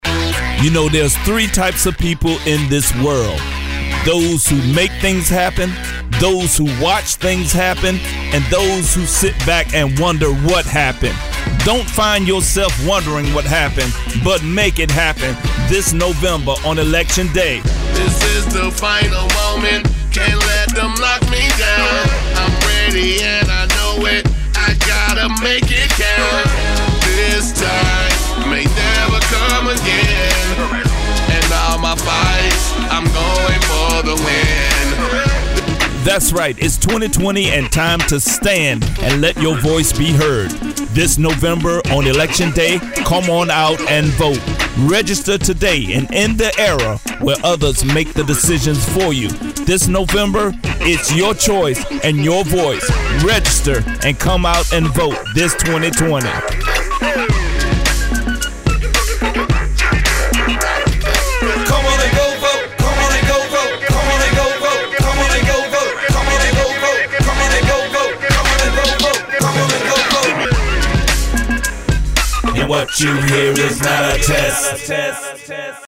He wanted to reach young voters so he mounted a billboard on the back of a truck, got one of his deacons to make a rap song, and drove up and down the streets of his North Carolina community playing it.